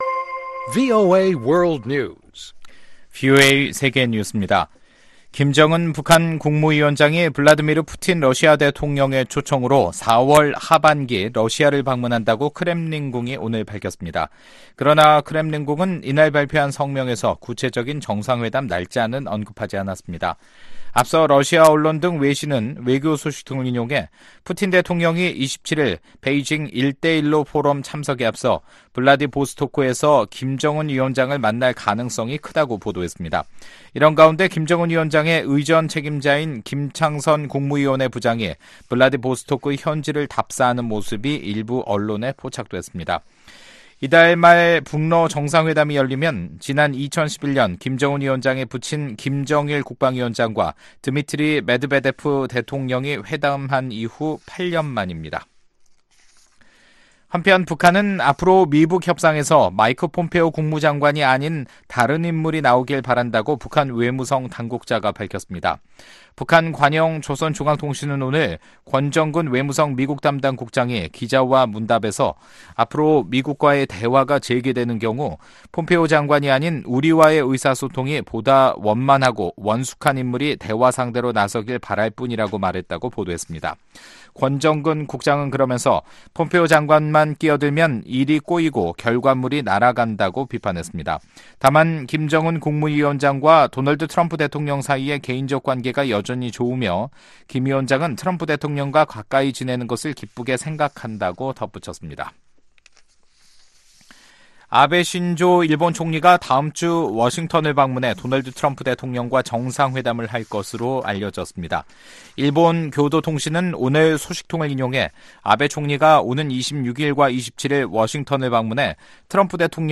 VOA 한국어 간판 뉴스 프로그램 '뉴스 투데이', 2019년 4월 18일 3부 방송입니다. 존 볼튼 백악관 국가안보보좌관은 3차 미-북 정상회담이 열리기 전에 김정은 북한 국무위원장이 핵무기를 포기할 준비가 됐다는 추가 증거가 필요하다고 밝혔습니다. 9명의 민주·공화 양당 상원의원들이 대북 압박 유지 방안을 논의하기 위해 한국을 방문했습니다.